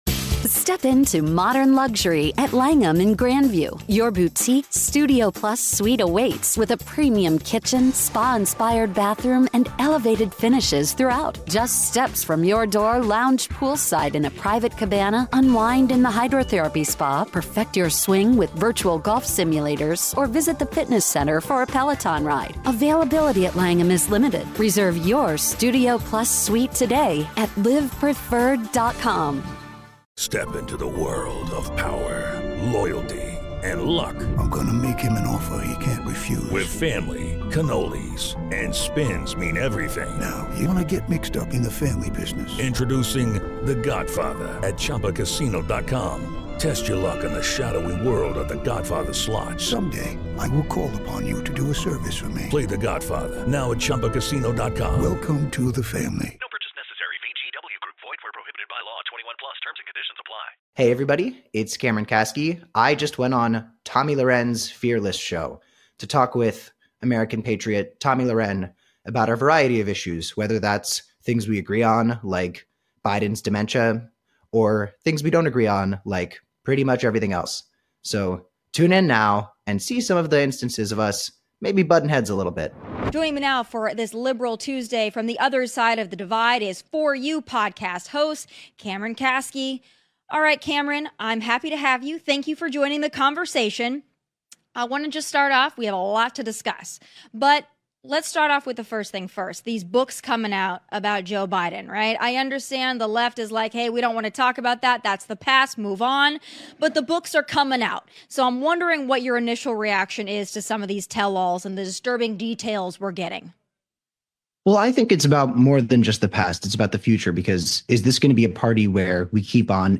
Cameron Kasky joins Tomi Lahren on her show, Tomi Lahren Is Fearless, to debate the DNC's messaging problems, the Trump admin's handling of immigration and trans women in sports.